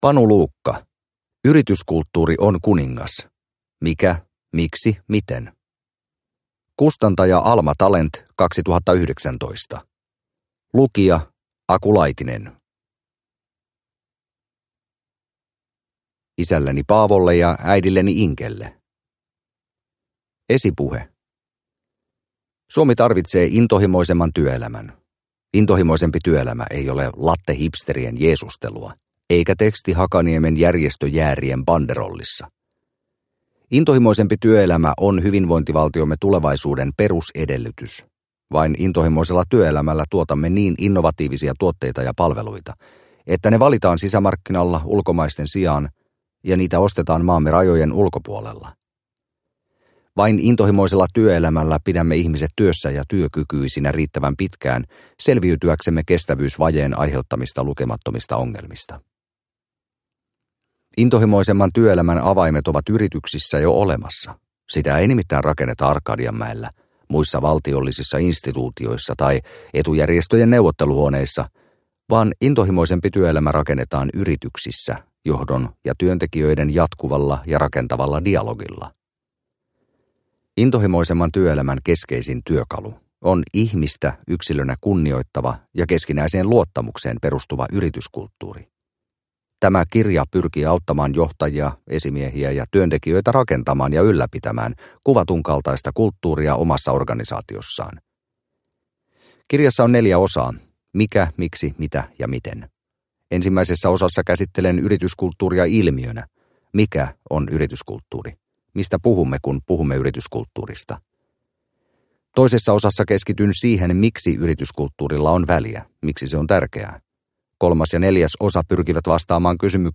Yrityskulttuuri on kuningas – Ljudbok